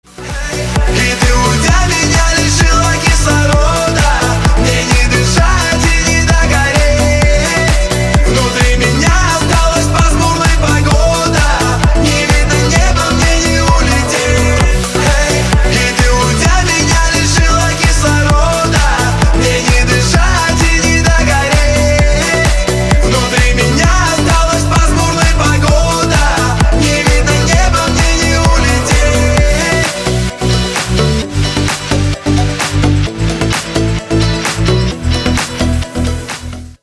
• Качество: 320, Stereo
мужской вокал
громкие
dance
Electronic
Club House
электронная музыка